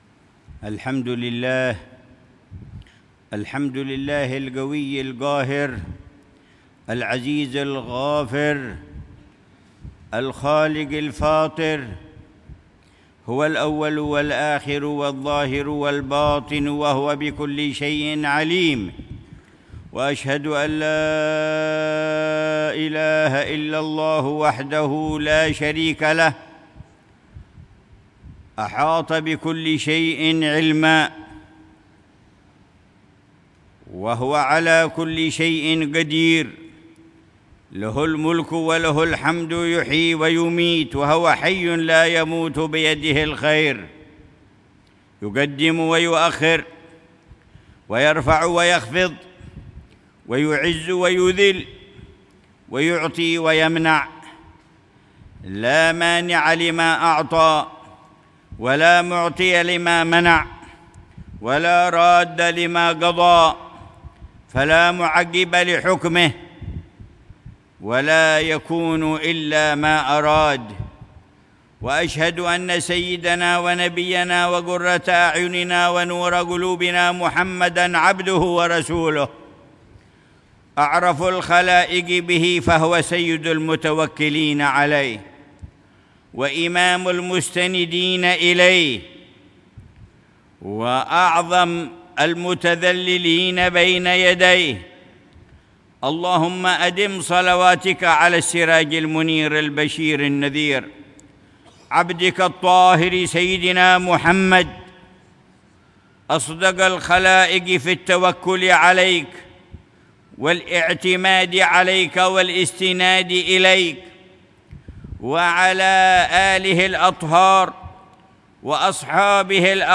خطبة الجمعة للعلامة الحبيب عمر بن محمد بن حفيظ، في مسجد الصحابي أبو عبيدة بن الجراح، في منطقة الغور الشمالي، الأردن، 28 صفر الخير 1447هـ بعنوان: